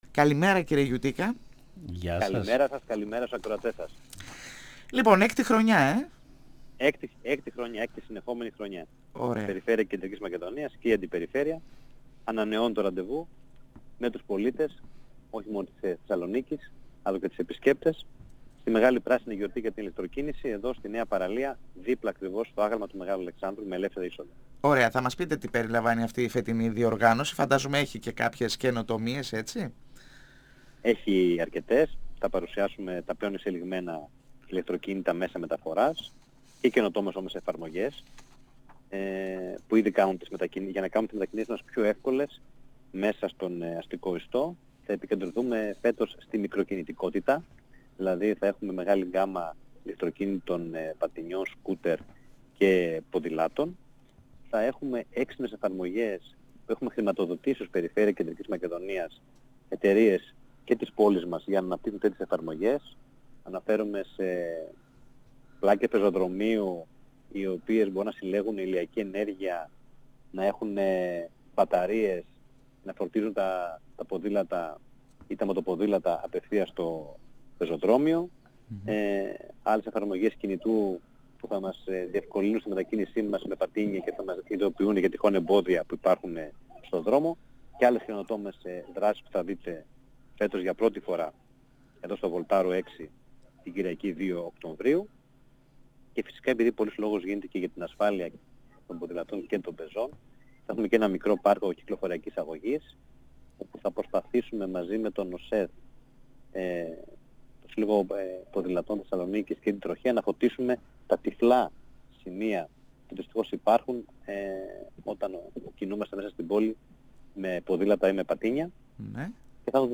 Ο αντιπεριφερειάρχης Ανάπτυξης και Περιβάλλοντος Κώστας Γιουτίκας στην εκπομπή «Εδώ και Τώρα» στον 102 FM | 29.09.2022